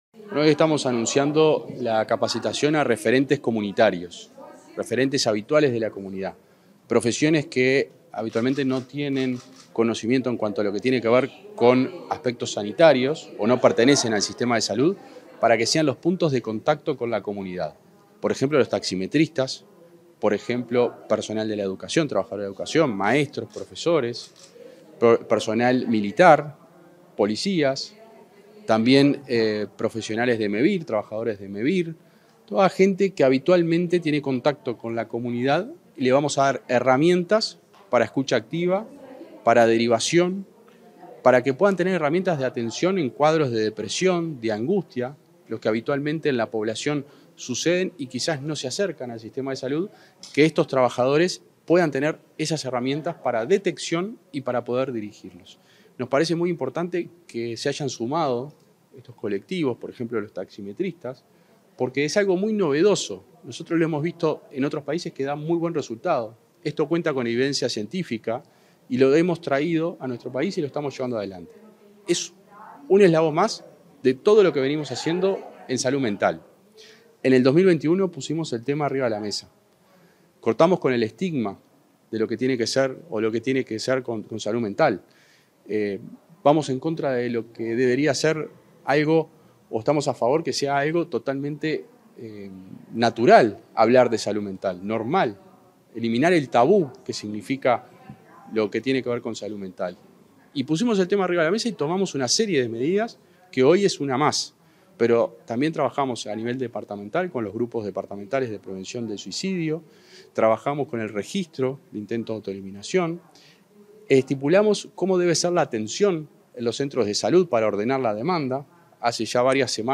Declaraciones del subsecretario de Salud Pública, José Luis Satdjian
Declaraciones del subsecretario de Salud Pública, José Luis Satdjian 11/10/2024 Compartir Facebook X Copiar enlace WhatsApp LinkedIn Tras el lanzamiento de la estrategia de capacitación de referentes comunitarios de los programas de salud mental y adolescencia, este 11 de octubre, el subsecretario de Salud Pública, José Luis Satdjian, realizó declaraciones a la prensa.